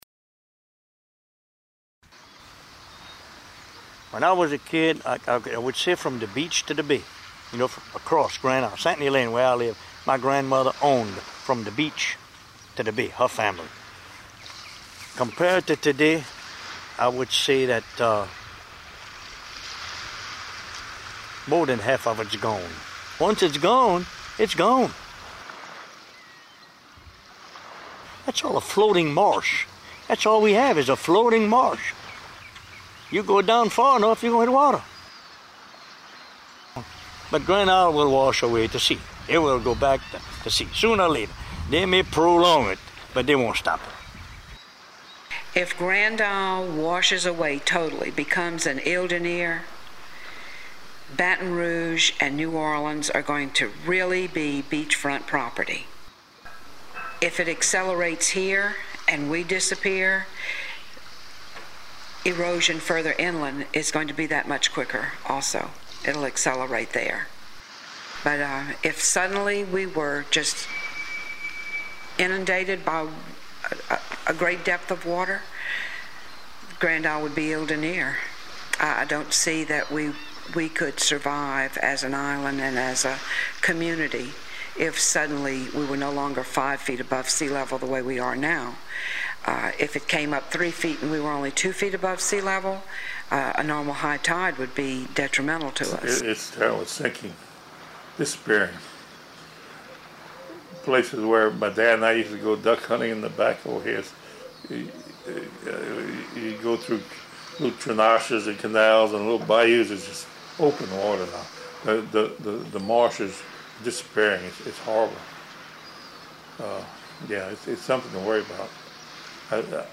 The soundscape offers more than a simple collection of oral histories.